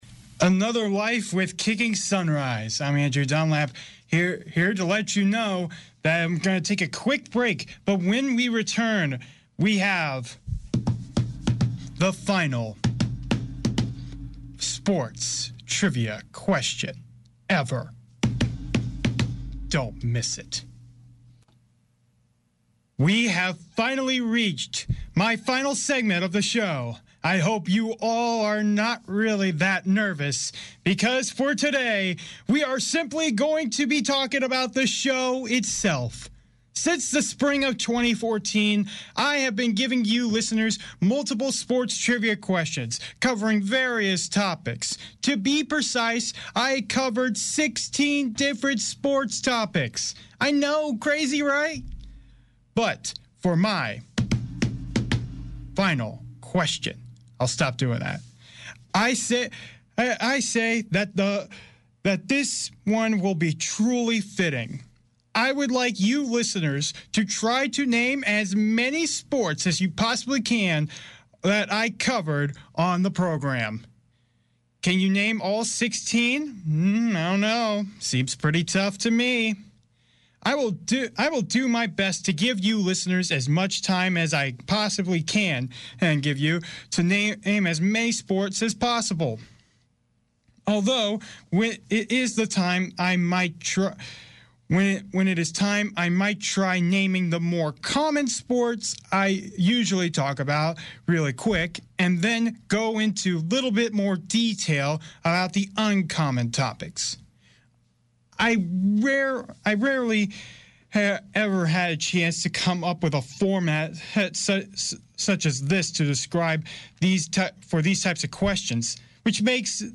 College Radio
This recording is postgame talk after commentating a Women’s Basketball game that went into triple overtime. My partner and I, do a summery of what we saw out there and the deciding factors in the game. We were both showed off our passion and excitement we had calling such a game.